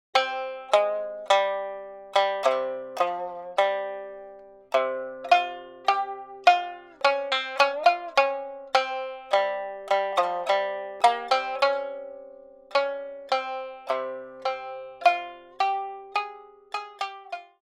Beginner-friendly song for shamisen.
• niagari tuning (C-G-C)